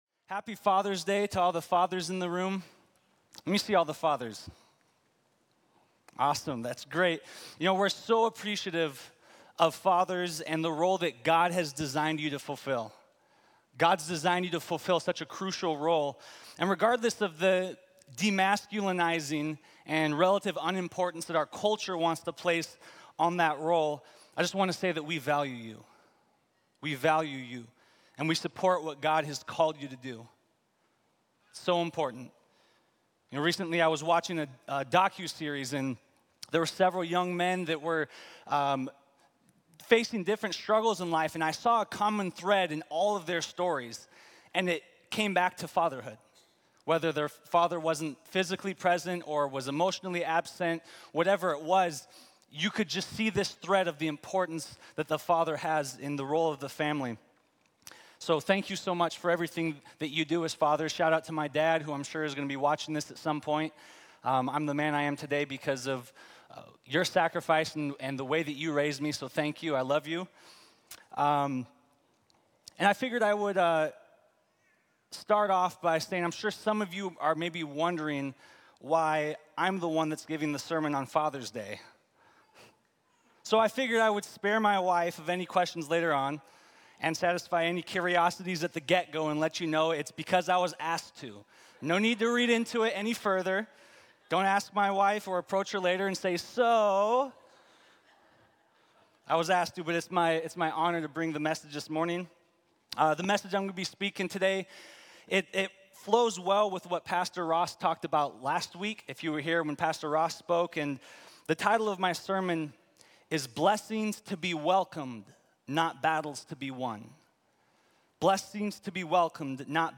Blessings To Be Welcomed, Not Battles To Be Won | Times Square Church Sermons